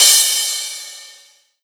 MicroDexed / addon / Drums / Crash1.wav
Crash1.wav